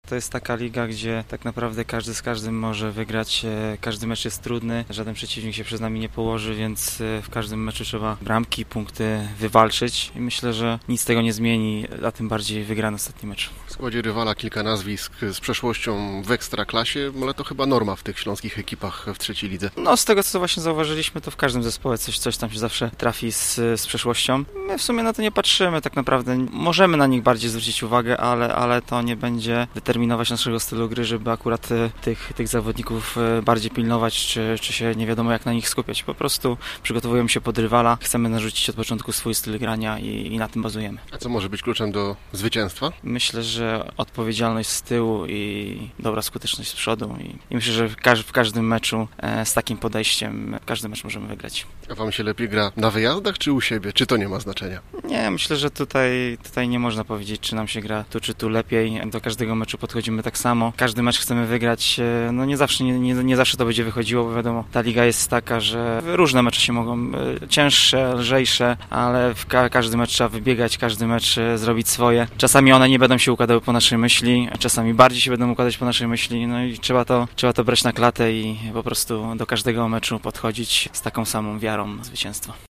Po meczu piłkarze Lechii przyznawali, że zagrali źle